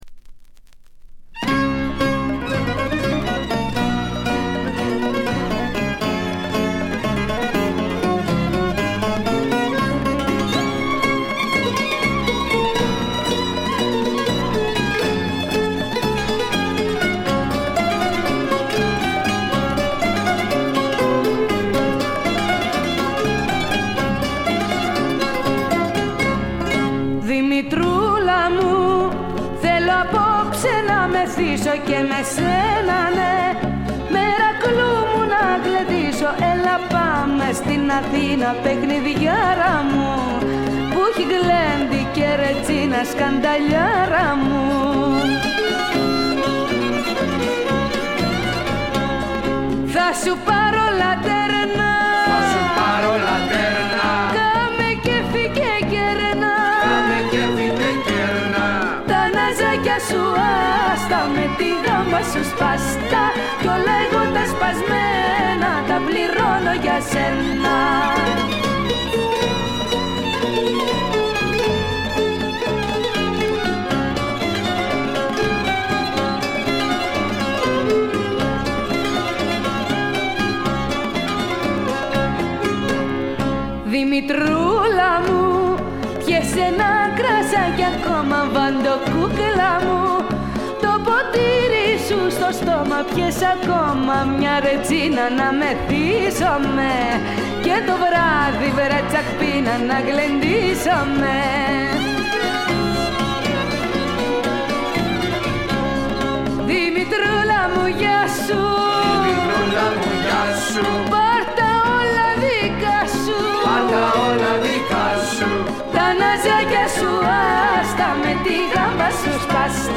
わずかなノイズ感のみ。
しかしデビュー時にしてすでに堂々たる歌唱を聴かせてくれます。
試聴曲は現品からの取り込み音源です。